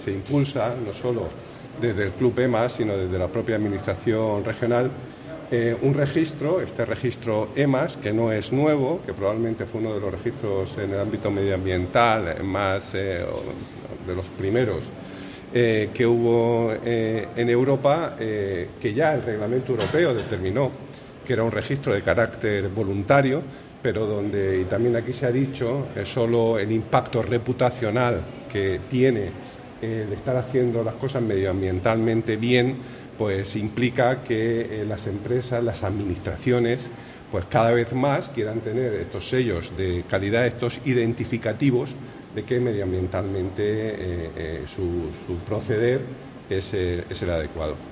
El consejero de Medio Ambiente, Universidades, Investigación y Mar Menor, Juan María Vázquez, sobre lo que supone el Registro EMAS.
El consejero Juan María Vázquez destaca el compromiso del tejido empresarial murciano con la mejora continua y la transparencia ambiental durante la jornada celebrada en la Universidad Politécnica de Cartagena